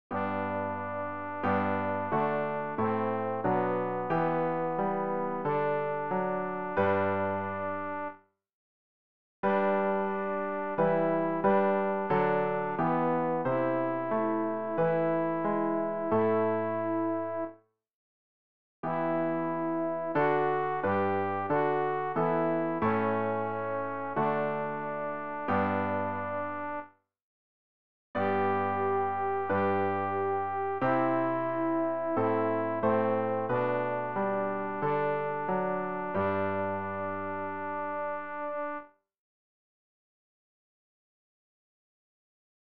alt-rg-089-ihr-knechte-gottes-allzugleich.mp3